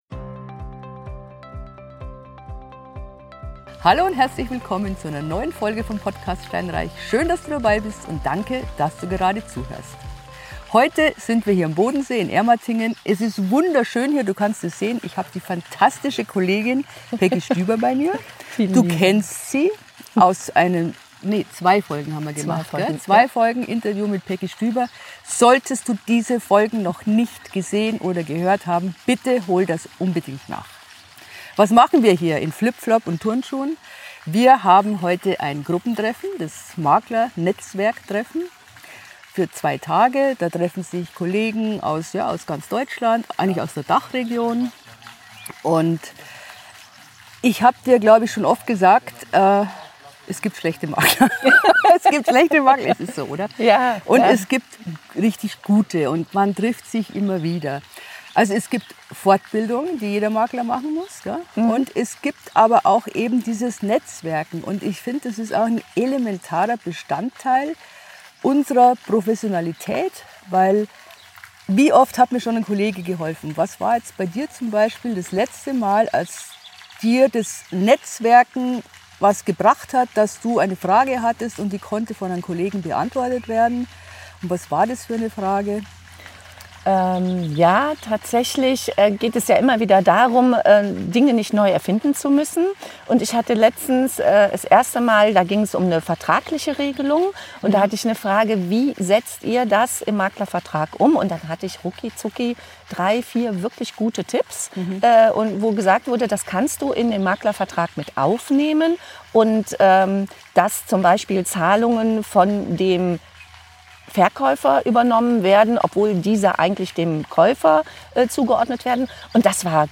Ein spannendes Gespräch in herrlicher Atmosphäre, mit einigen Learnings für dich.